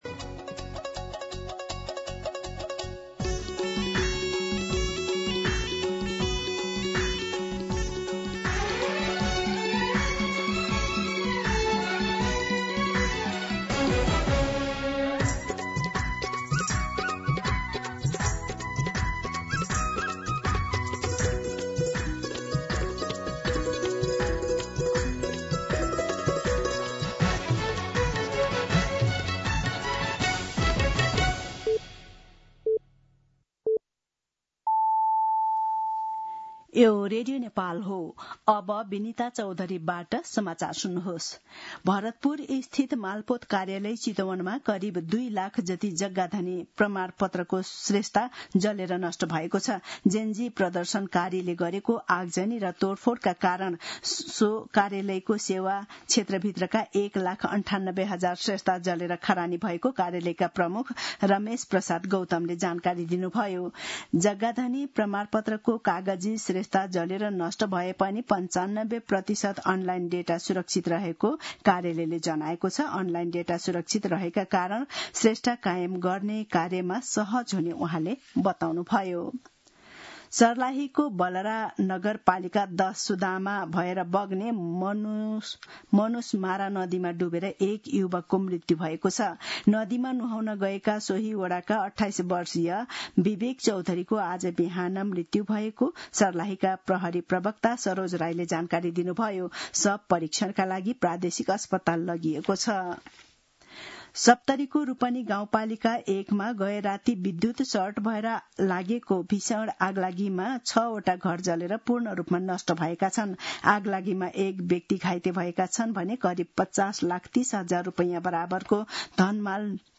मध्यान्ह १२ बजेको नेपाली समाचार : २४ असोज , २०८२